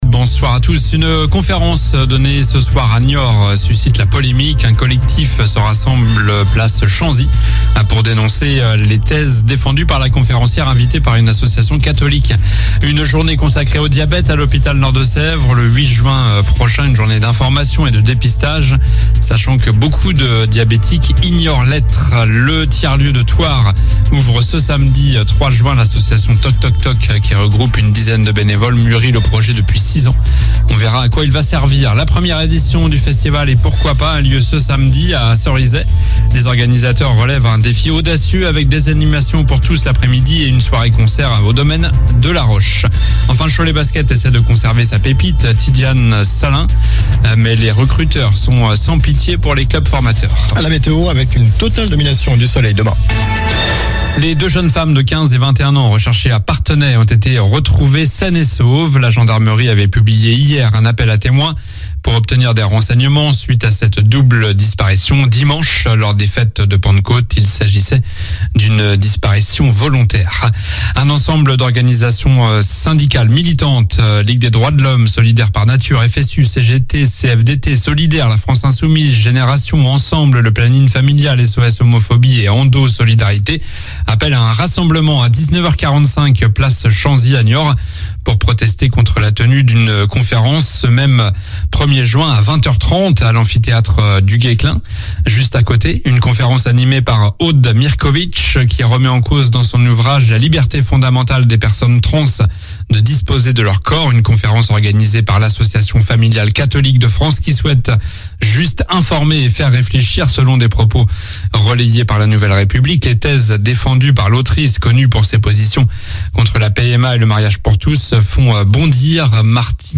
Journal du jeudi 1er Juin (soir)